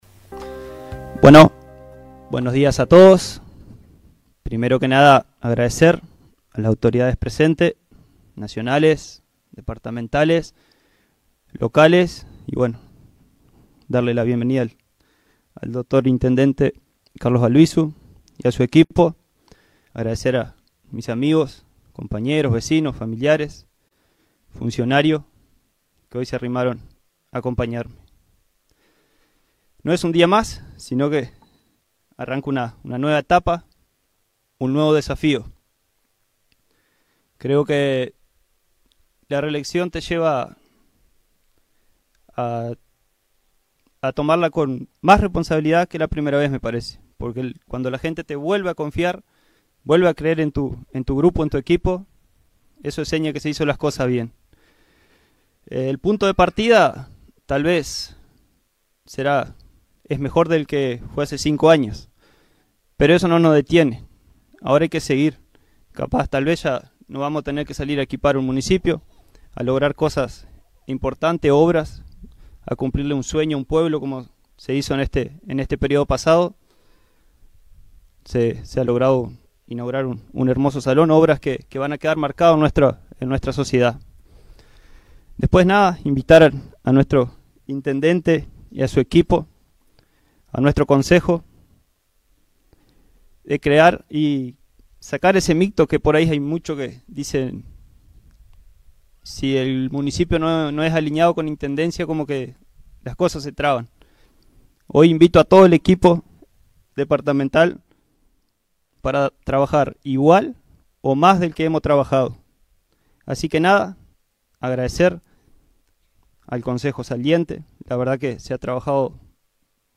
Este sábado, en el marco de una ceremonia que contó con la presencia de autoridades nacionales, departamentales y locales, Santiago Dalmao asumió nuevamente como alcalde del Municipio de Rincón de Valentín para el período 2025-2030, acompañado por los concejales que integrarán el nuevo Consejo Municipal.
A su turno, Santiago Dalmao, visiblemente emocionado, comenzó sus palabras agradeciendo a las autoridades presentes, a su familia, amigos, vecinos y funcionarios municipales que lo acompañaron en este nuevo inicio.